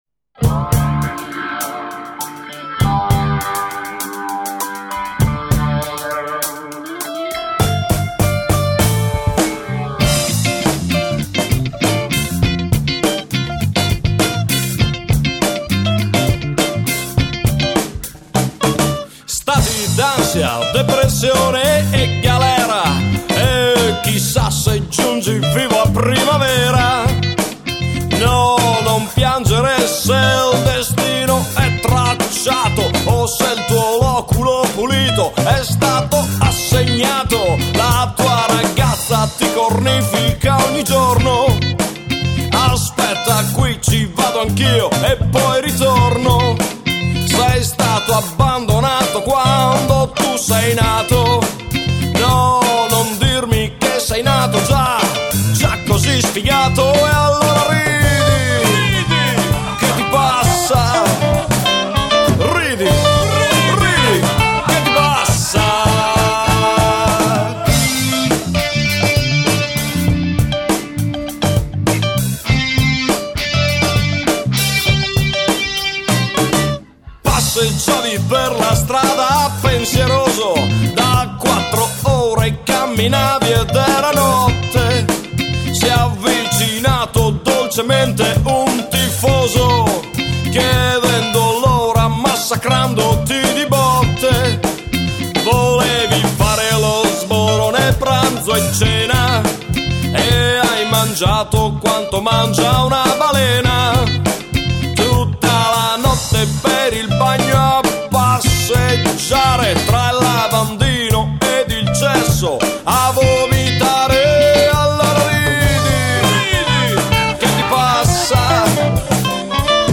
Musica Demenziale e altro...